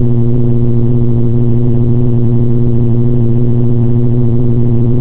spaceEngineLow_003.ogg